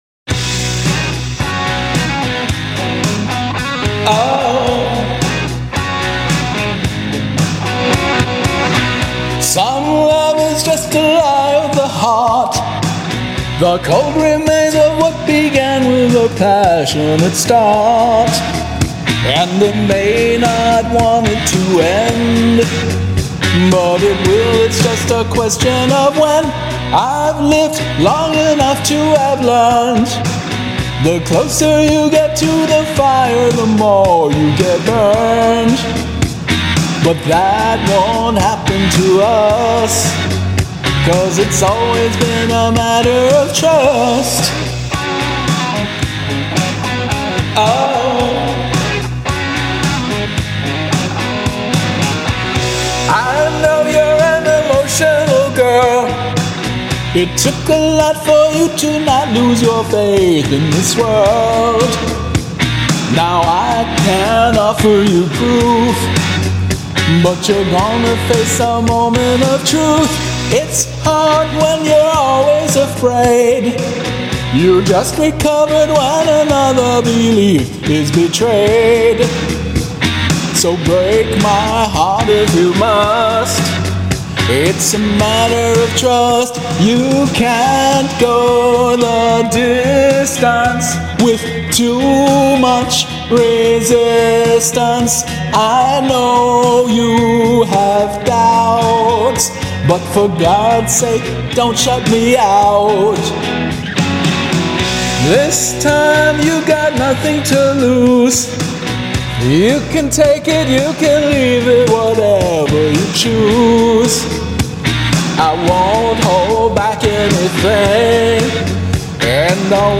vocals
karaoke arrangement